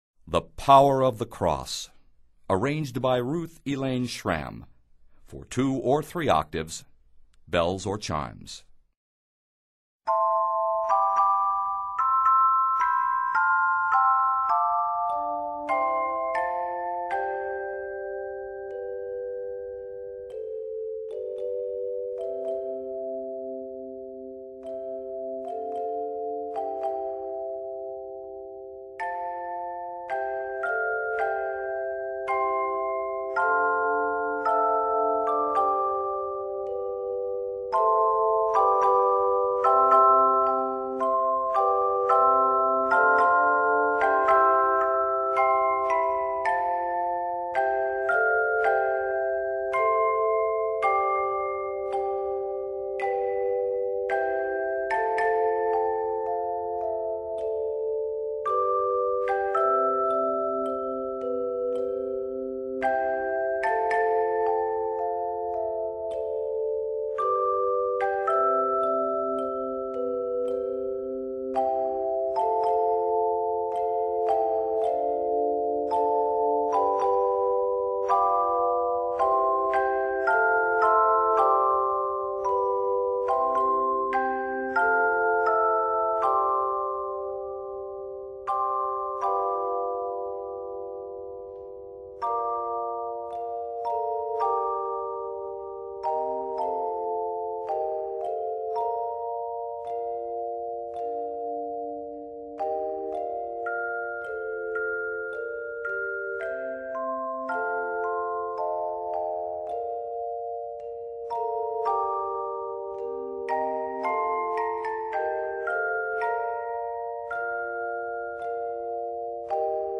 A major second modulation (from C Major to Bb Major)
Octaves: 2-3